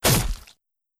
Melee Weapon Attack 14.wav